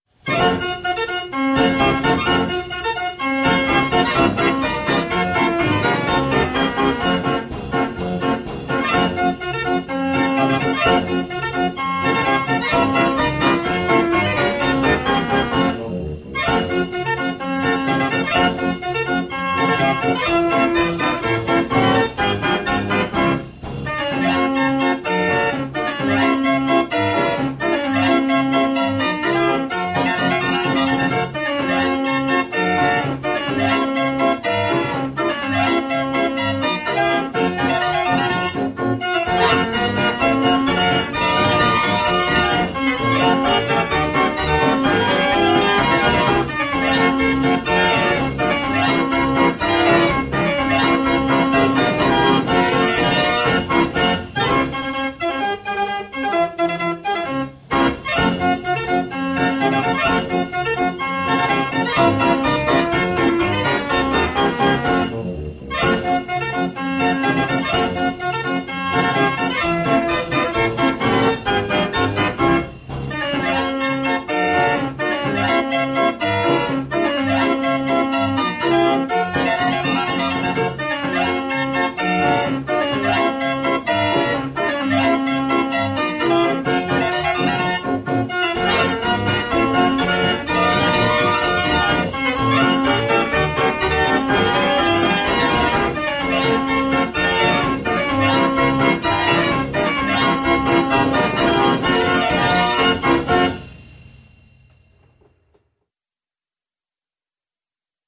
Historisches Drehorgeltreffen in Lichtensteig (SG)
Grosse Trommel mit Becken
Kleine Trommel mit Wirbelsteuerung